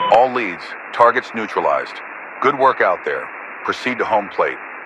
Radio-commandMissionComplete2.ogg